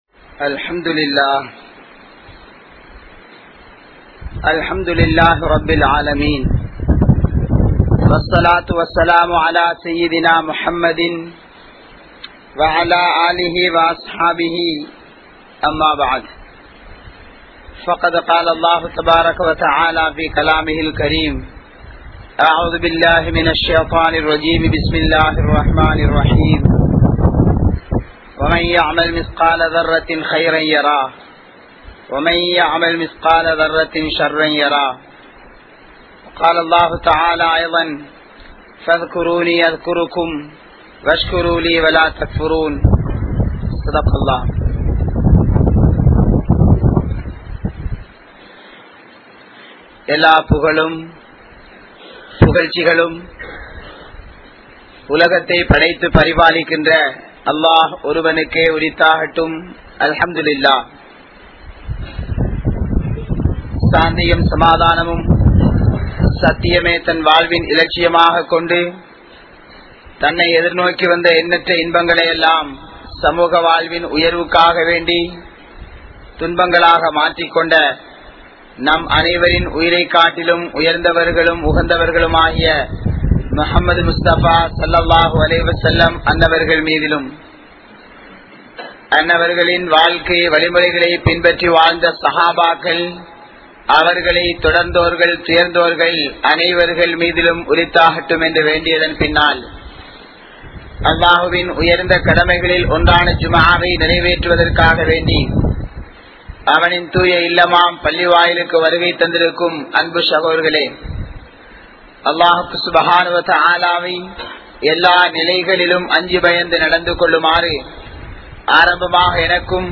Nalla Seyalhalai Seiungal (நல்ல செயல்களை செய்யுங்கள்) | Audio Bayans | All Ceylon Muslim Youth Community | Addalaichenai